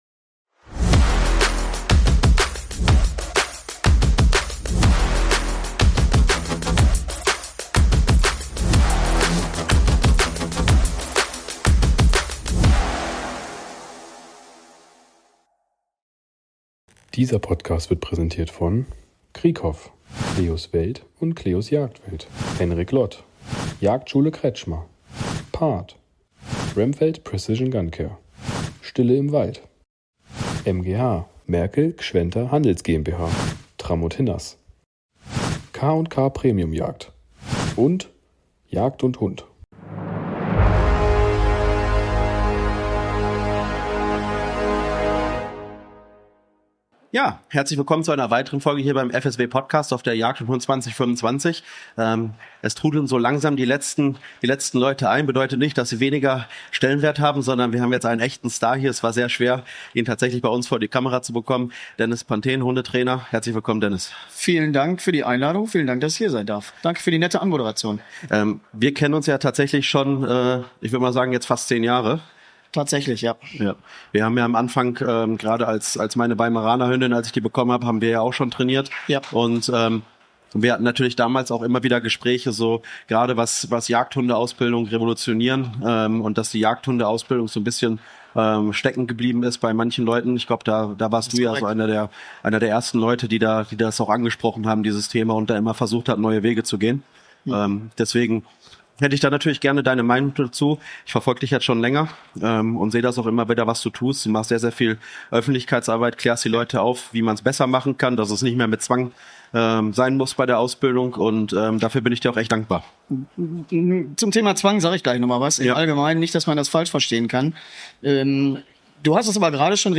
Beschreibung vor 1 Jahr Willkommen auf der Jagd & Hund 2025! Wir sind live auf Europas größter Jagdmesse unterwegs und sprechen mit spannenden Gästen aus der Jagdszene.